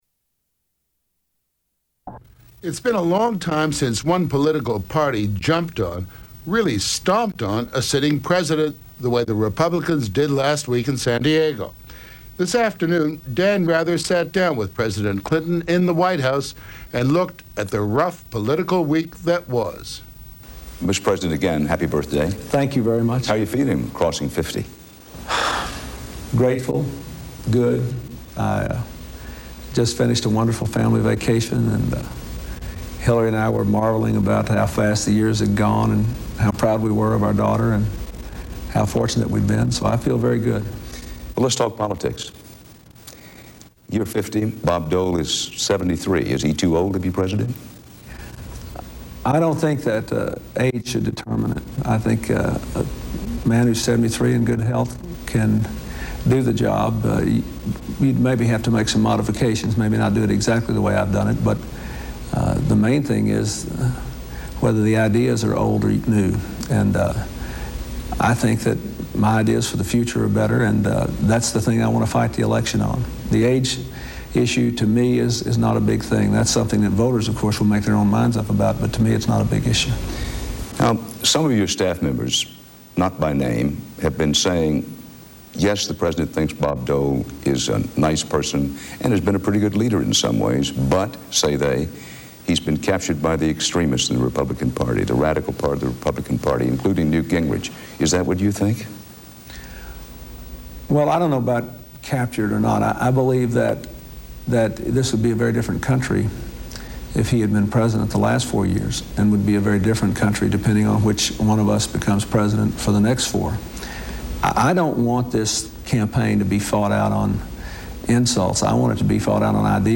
Clinton says he does not plan on raising taxes but does plan to sign the Minimum Wage Bill. On "60 Minutes."